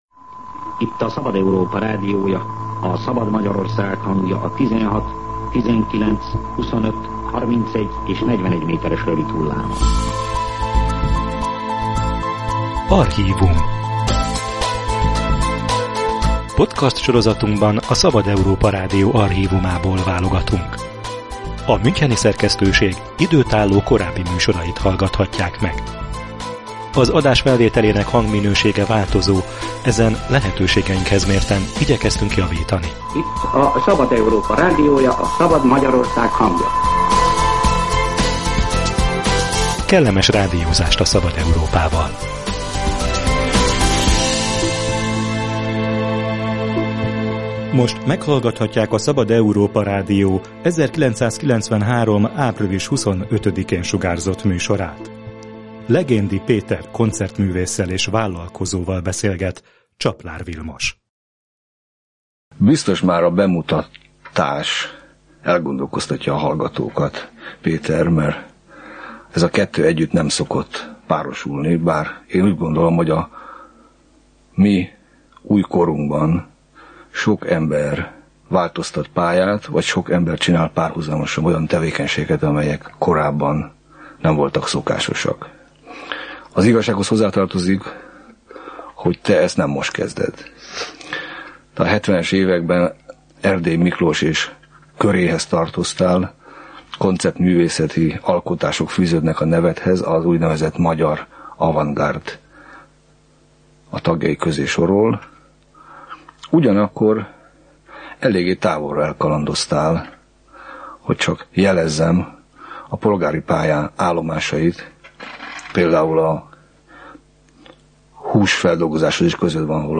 archív beszélgetés